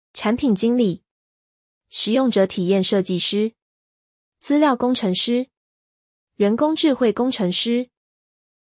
プロダクトマネージャー 產品經理 chǎn pǐn jīng lǐ 台湾では「產品＝プロダクト」。